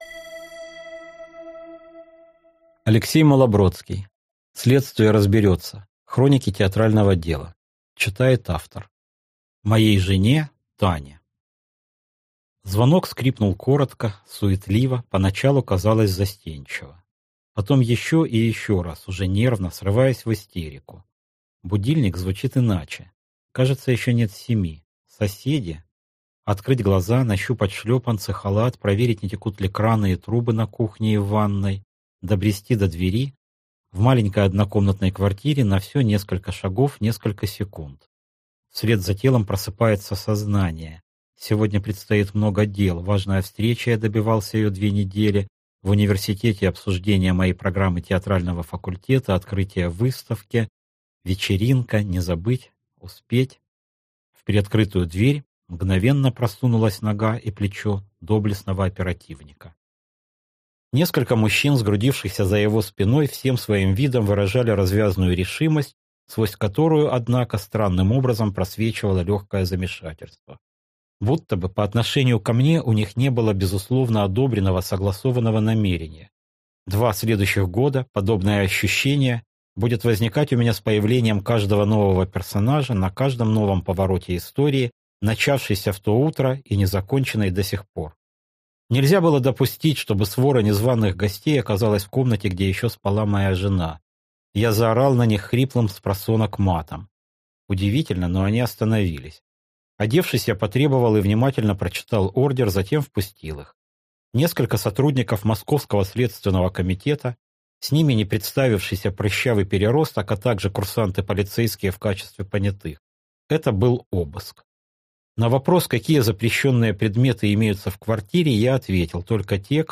Аудиокнига Следствие разберется. Хроники «театрального дела» | Библиотека аудиокниг